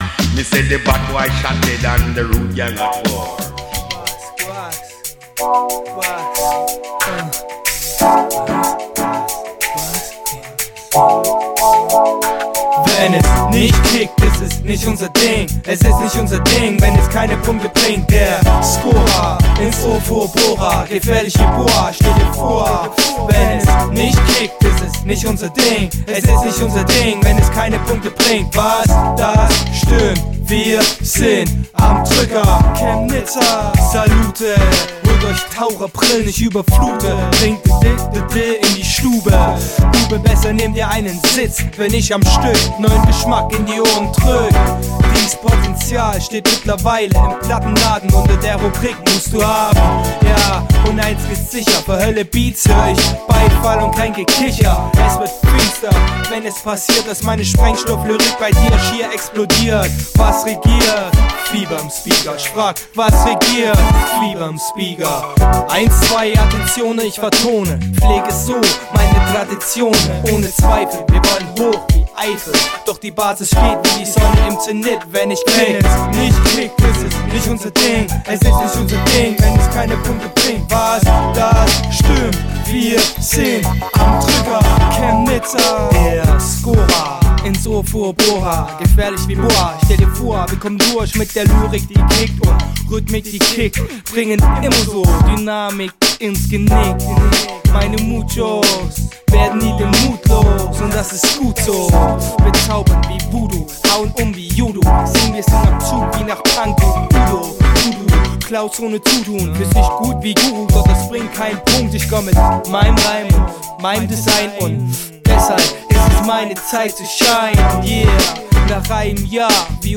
Free Underground Web-Radio,
Hip Hop - Deutsch RAP